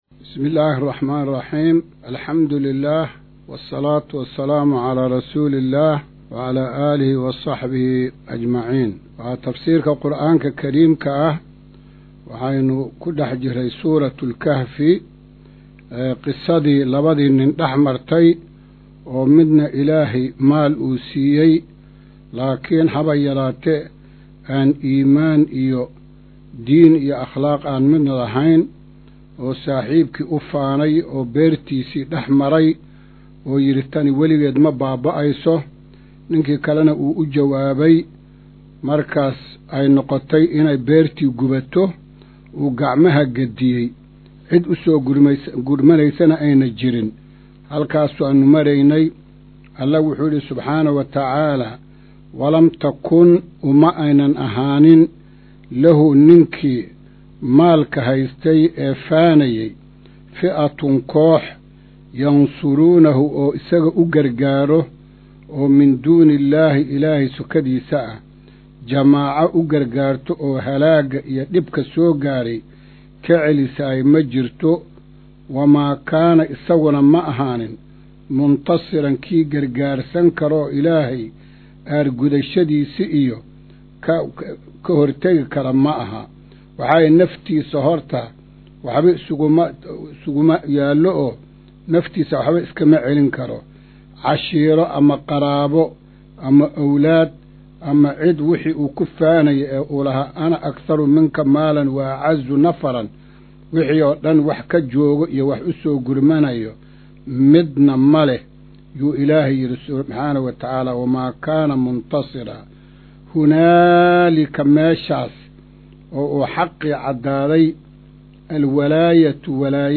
Maqal:- Casharka Tafsiirka Qur’aanka Idaacadda Himilo “Darsiga 145aad”